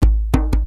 PERC 05.AI.wav